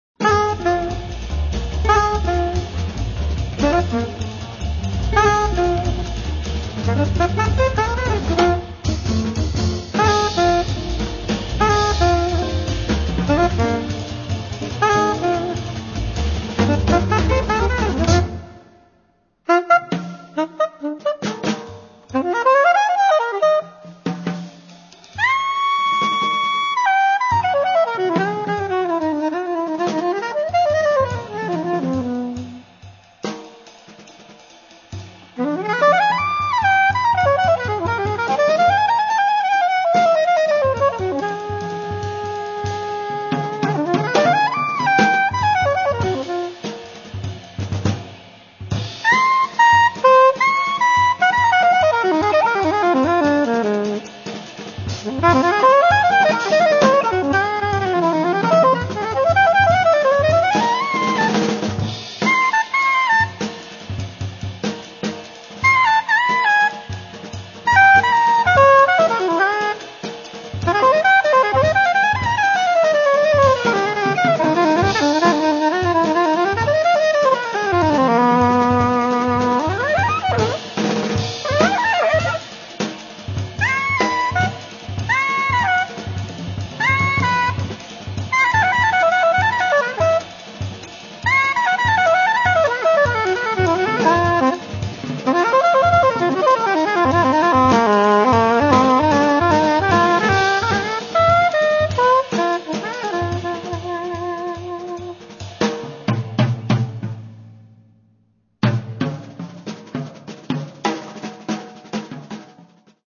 soprano and tenor sax
drums
acoustic guitar
double bass
swing fast